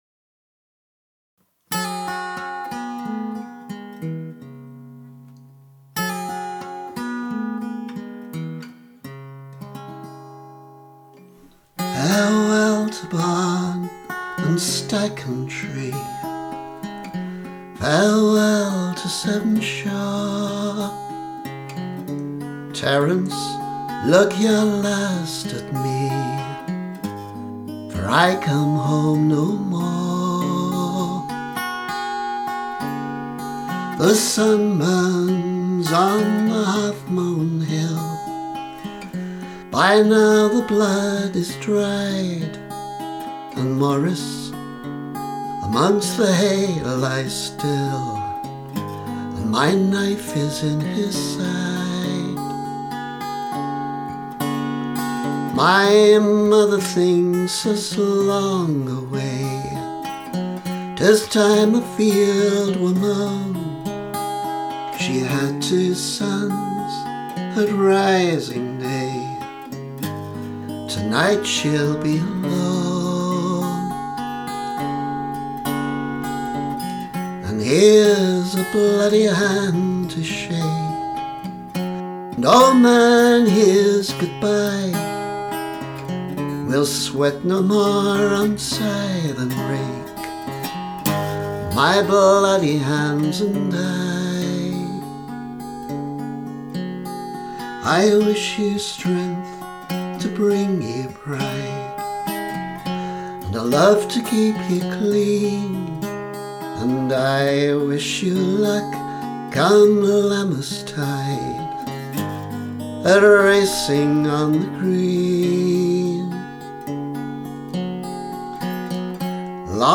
Mastered audio capture of the performance:
Homestudio recording